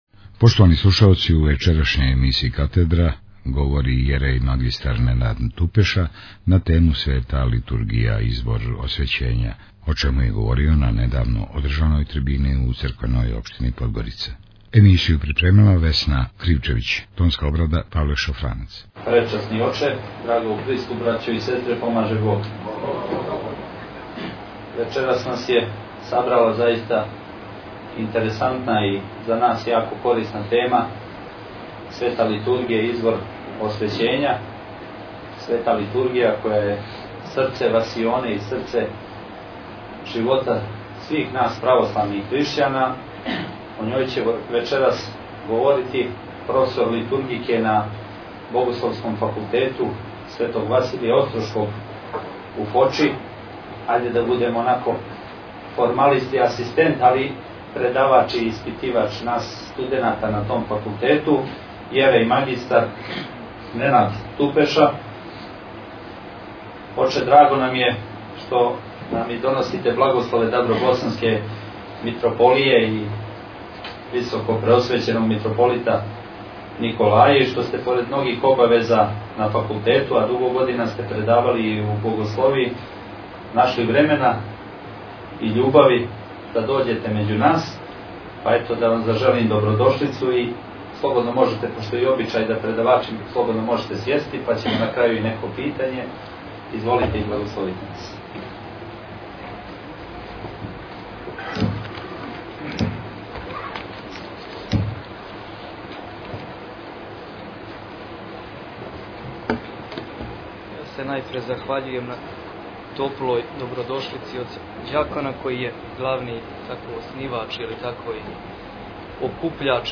Извођач: © Radio Svetigora 2008 Наслов: emisija Година: 2008 Величина: 58:13 минута (8.34 МБ) Формат: MP3 Mono 11kHz 20Kbps (VBR) Јереј мр.
извор освећења» на трибини у Црквеној општини Подгорица.